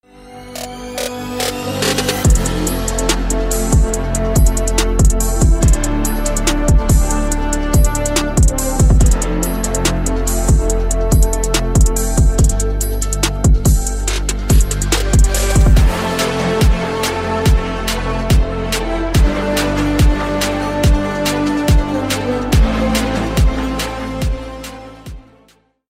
Kategoria Elektroniczne